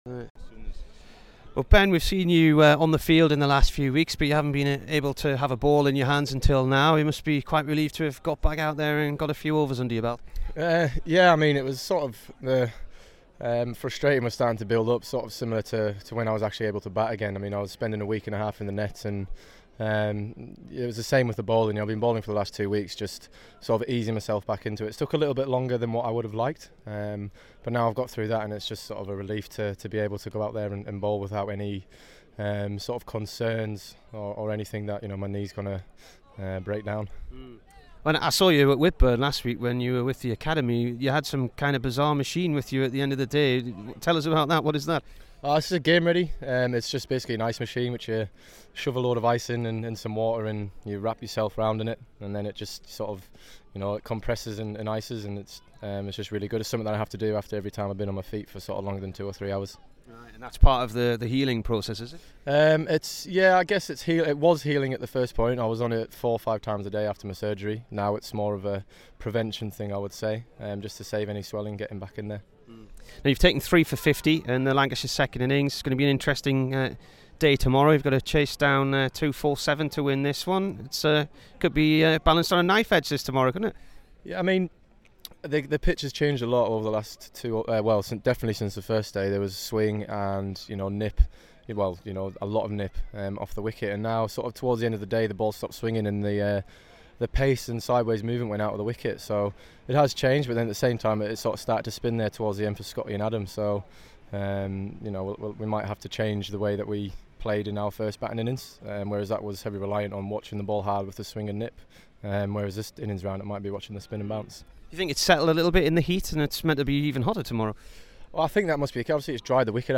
Ben Stokes int